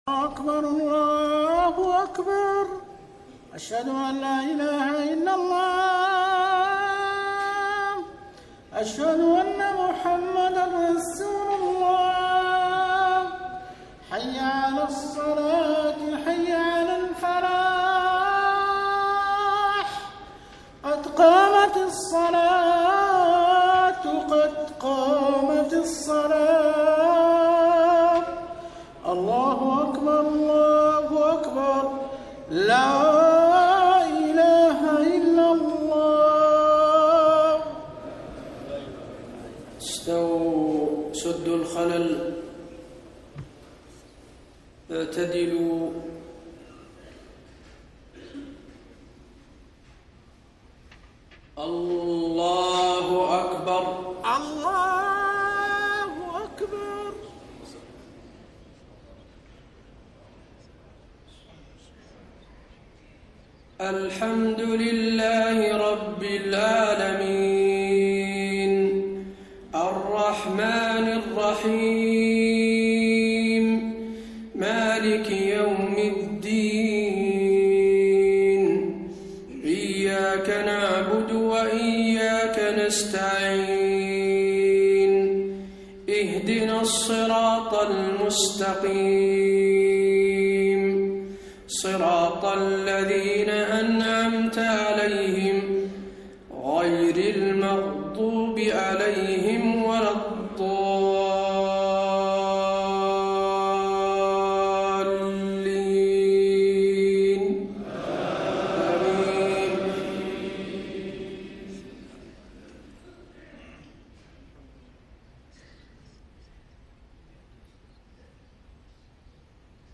صلاة المغرب 10 ربيع الأول 1431هـ فواتح سورة الذاريات 1-23 > 1431 🕌 > الفروض - تلاوات الحرمين